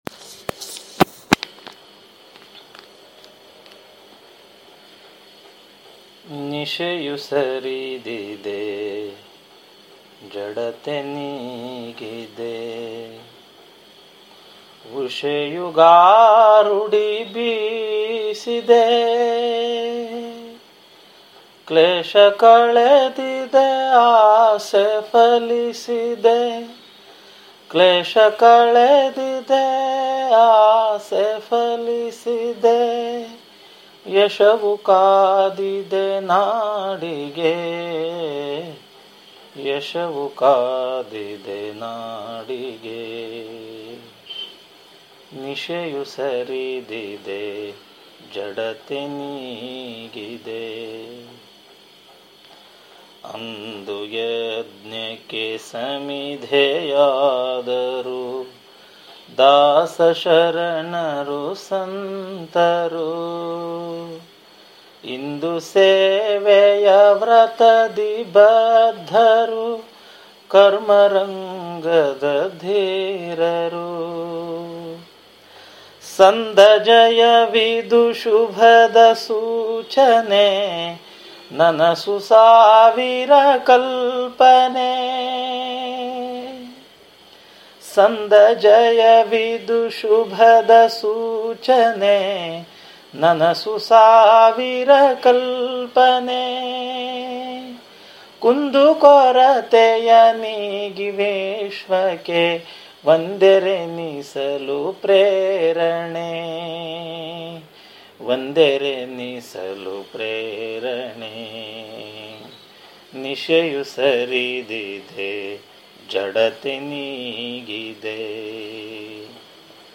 Patriotic Songs Collections
Solo